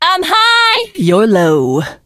janet_kill_vo_04.ogg